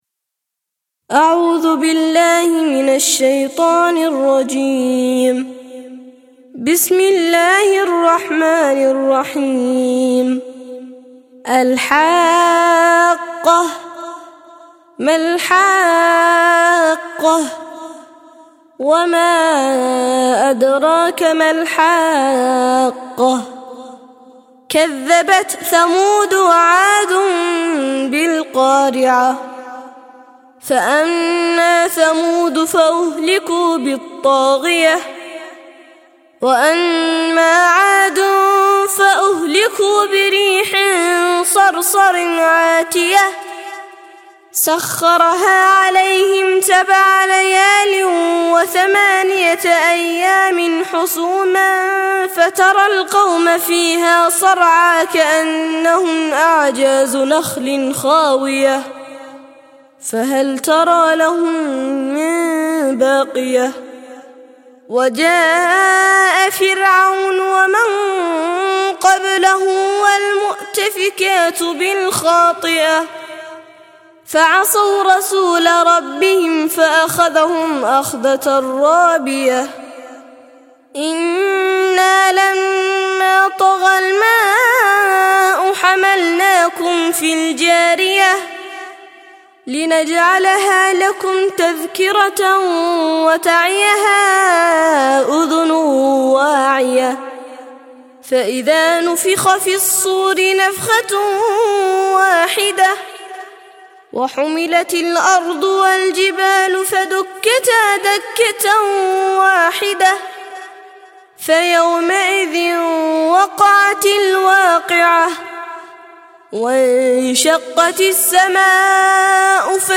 69- سورة الحاقة - ترتيل سورة الحاقة للأطفال لحفظ الملف في مجلد خاص اضغط بالزر الأيمن هنا ثم اختر (حفظ الهدف باسم - Save Target As) واختر المكان المناسب